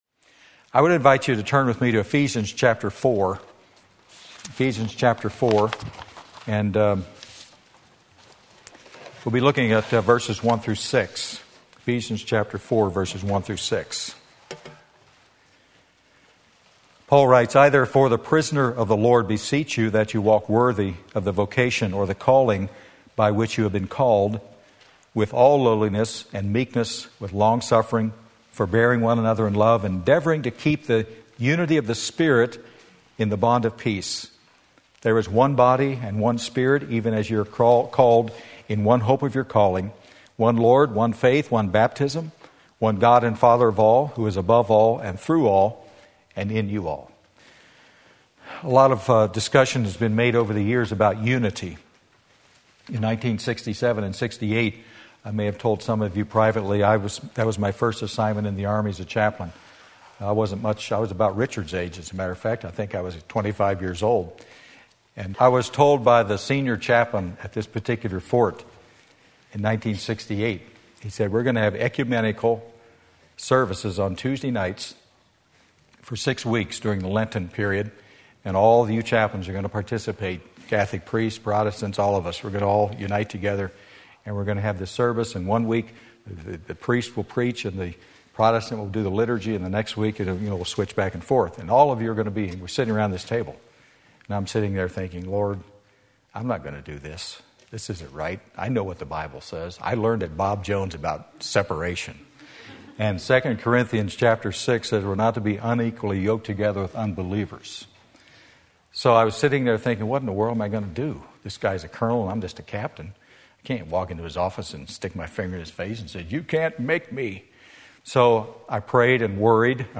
Sermon Link
Sunday Afternoon Service